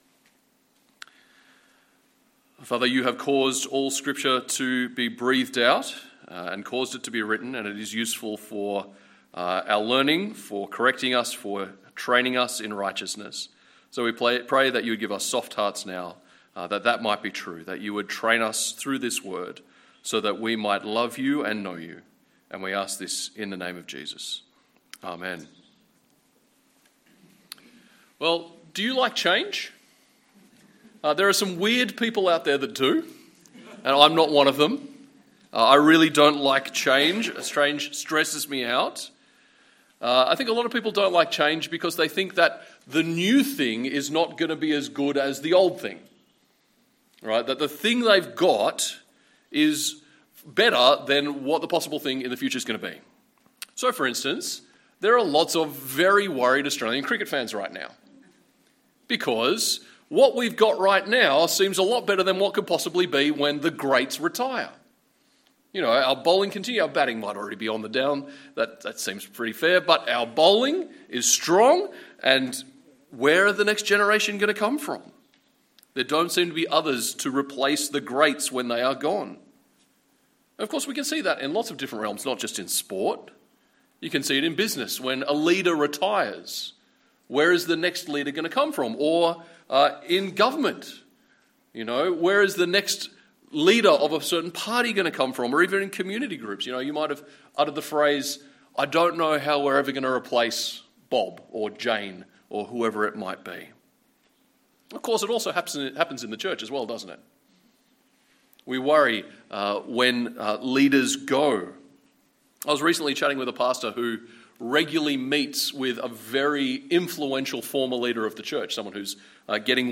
2025 The church after Paul Preacher